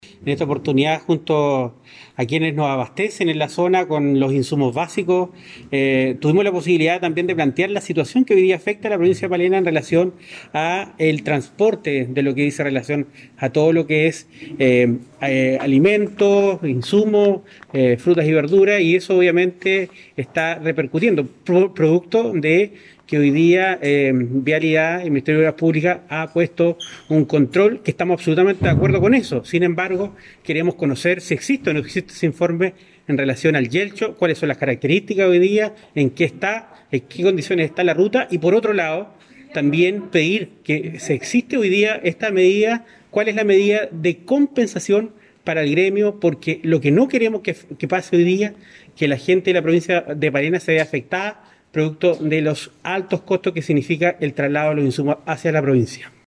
El Consejero Regional, Roberto Soto, explicó que el objetivo de estas gestiones es impedir que las comunidades más aisladas sigan siendo golpeadas con una excesiva alza en el costo de la vida.